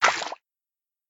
fish_flapping.ogg